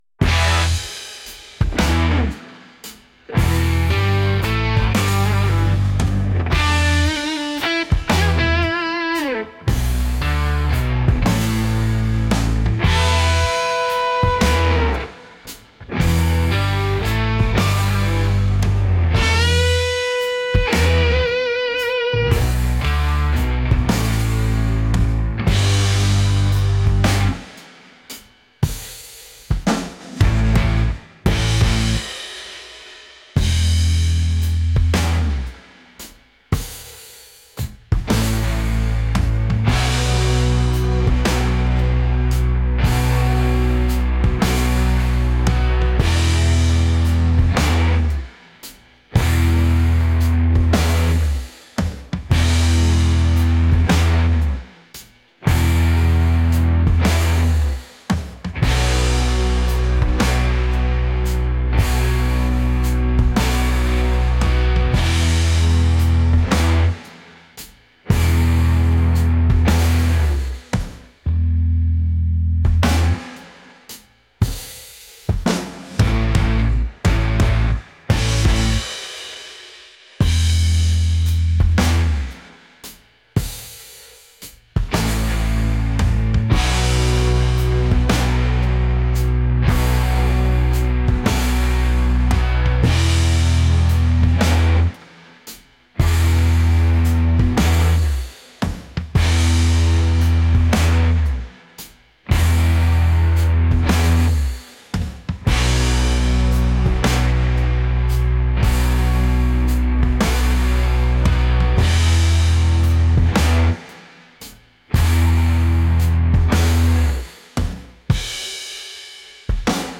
blues | soulful